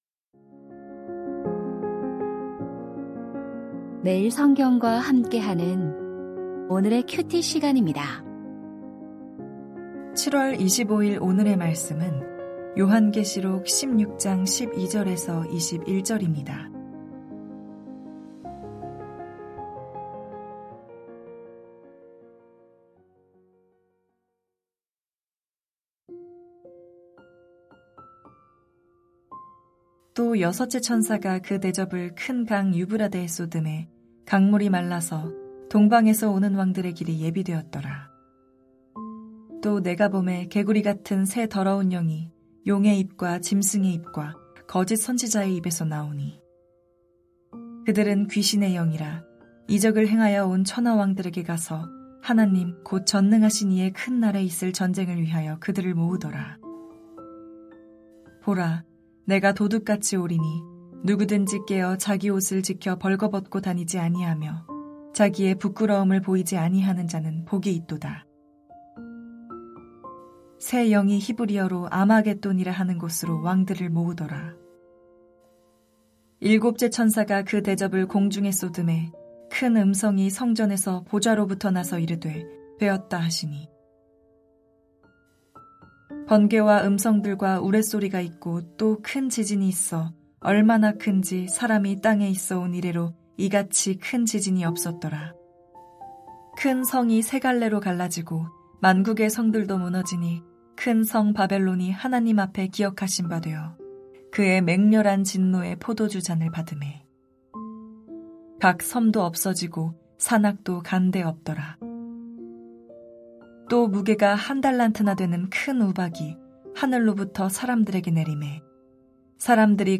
요한계시록 16:12-21 슬기로운 말세 생활 2025-07-25 (금) > 오디오 새벽설교 말씀 (QT 말씀묵상) | 뉴비전교회